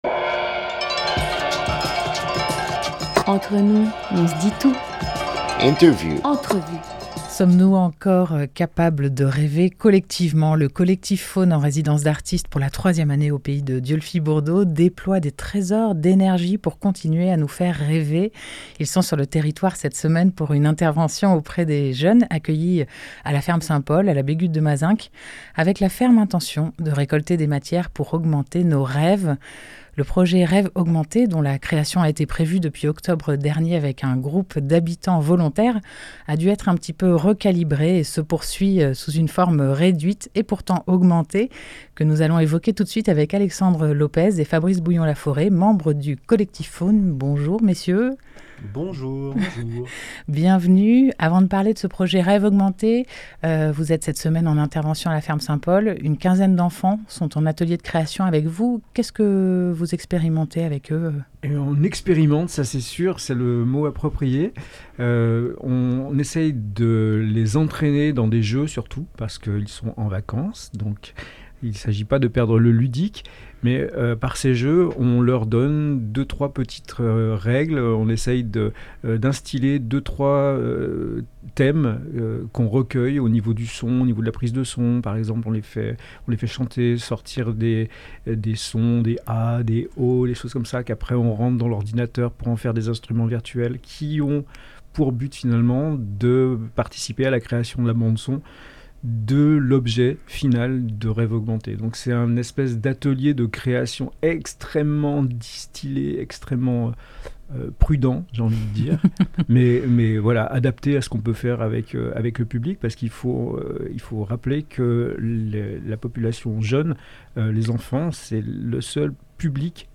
21 avril 2021 16:08 | culture, Interview